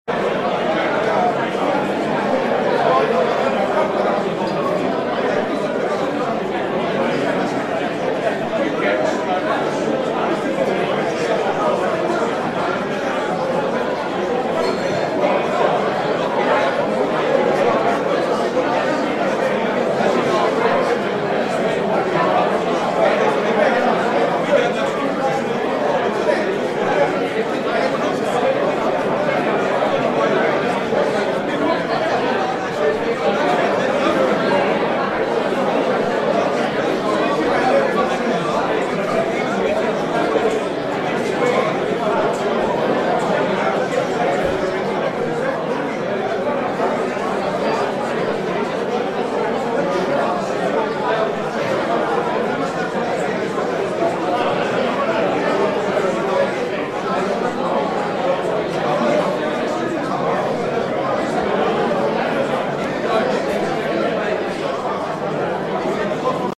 Free Bar Ambience Sound Effect
Category: Sound FX   Right: Personal